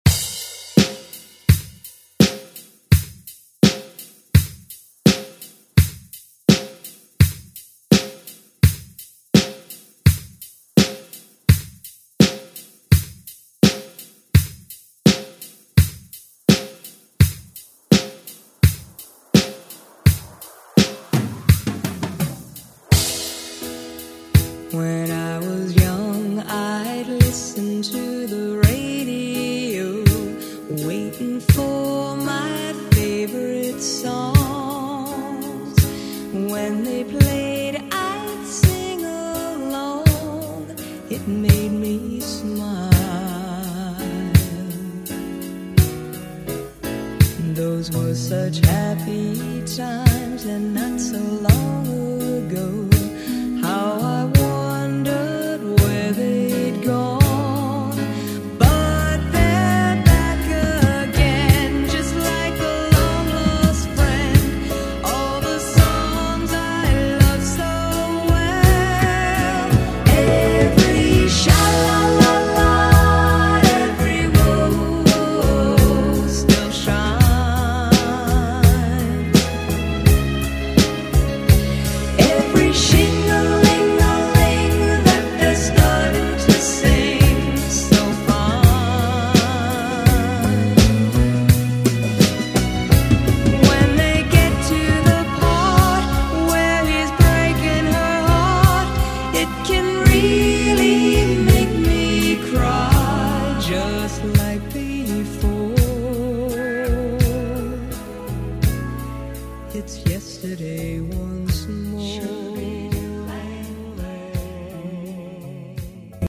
Rap Version
Electronic Pop Euro House Music
130 bpm